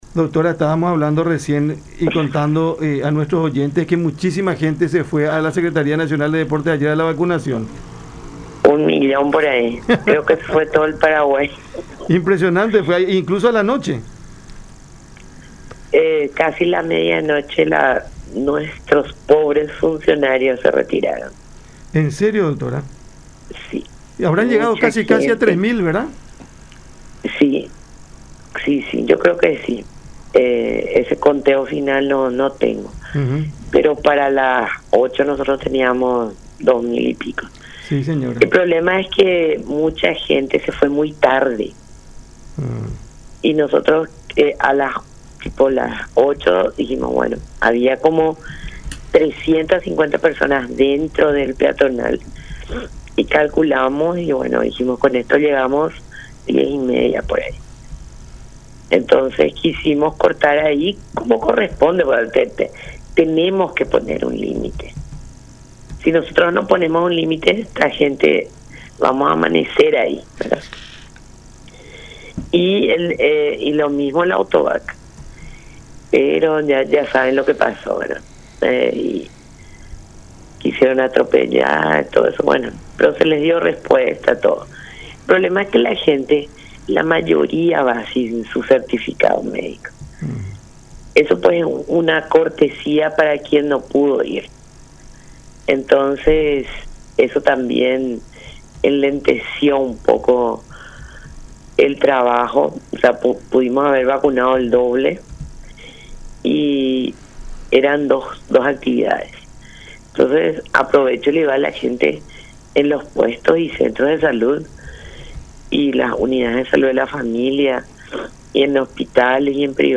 en conversación con Cada Mañana por La Unión